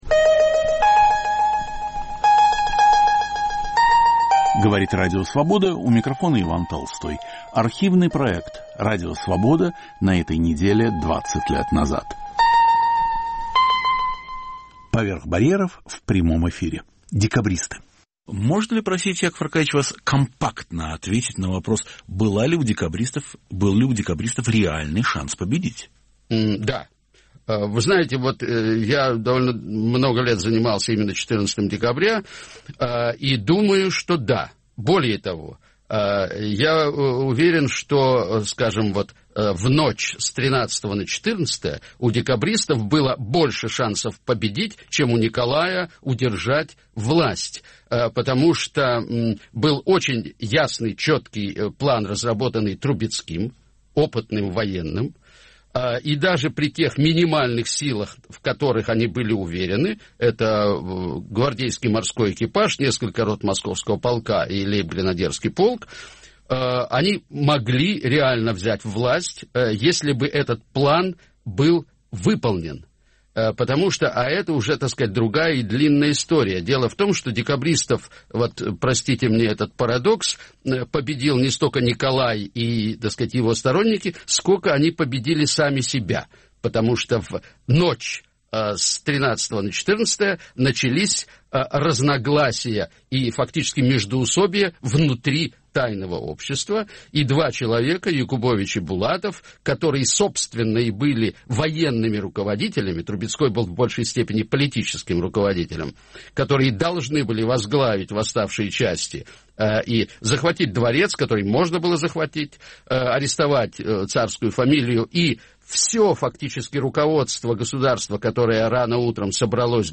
"Поверх барьеров" в прямом эфире. Декабристы
Был ли у декабристов шанс победить? В студии Радио Свобода писатель и историк Яков Гордин. Ведущий Иван Толстой.